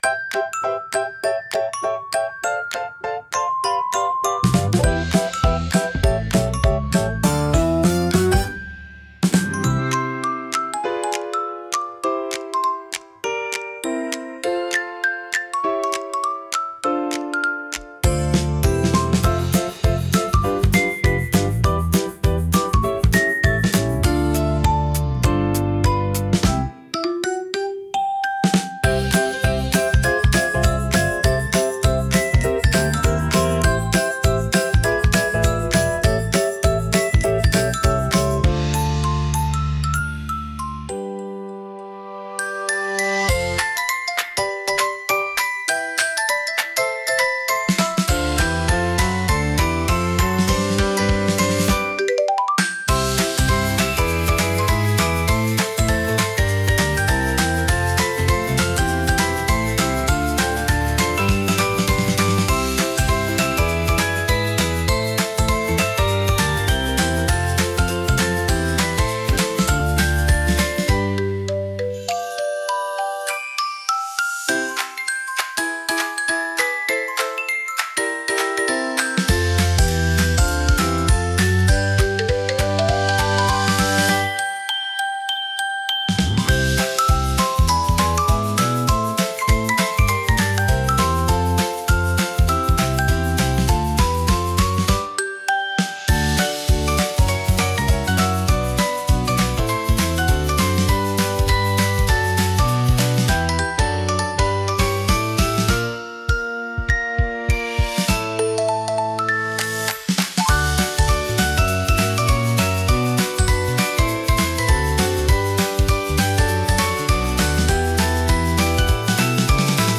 わくわくピコピコBGM